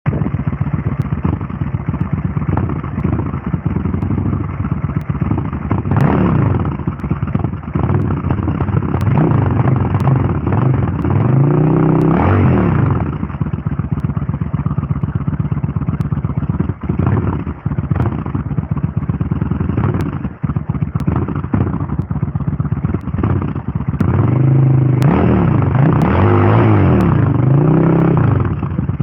Geluid Intruder  ====>